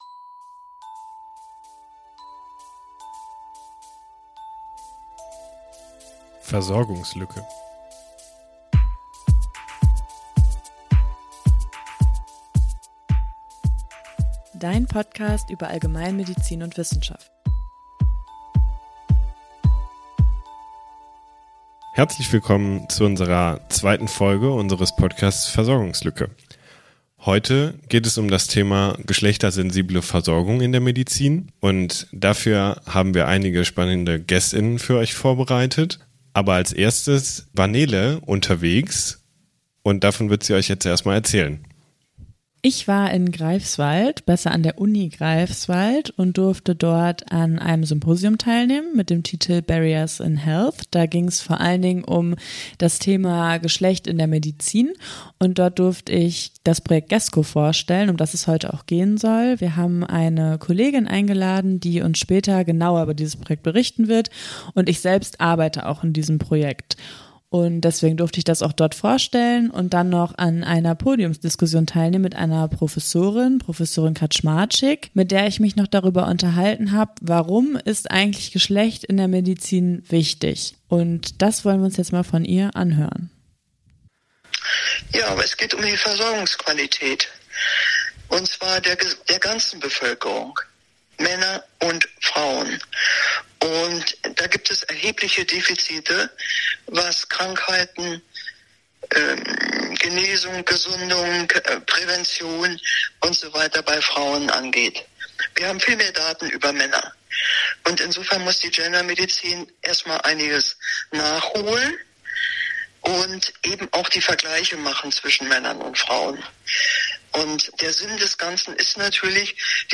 In der aktuellen Folge sprechen wir über GESCO, ein Pilotprojekt zur Entwicklung einer geschlechtessensiblen Versorgung von Menschen mit chronischen Schmerzen in der Allgemeinmedizin. Dazu haben wir mit Expert:innen aus dem Projekt, sowie aus den Bereichen Geschlechterforschung, Gender-Medizin und zum Thema Schmerz gesprochen.